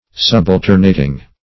Subalternating \Sub*al"ter*na`ting\, a.